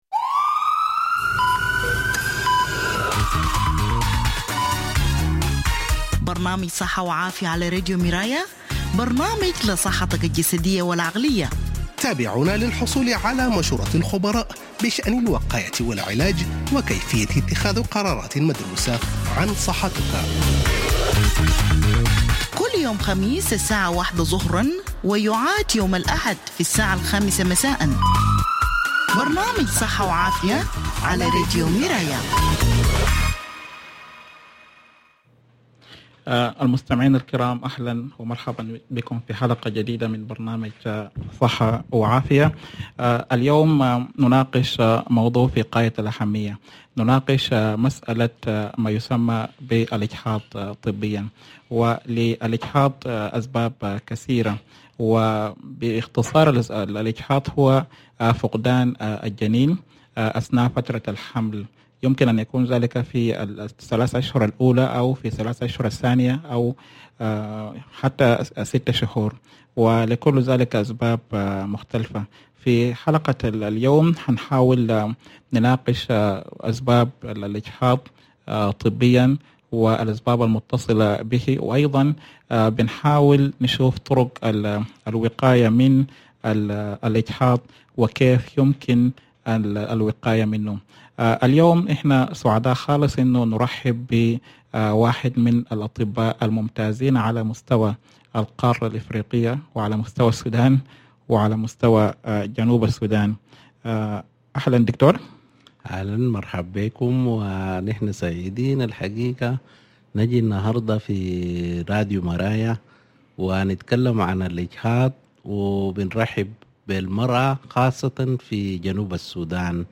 Obstetrician and Gynecologist Share Facebook X Subscribe Next MBS.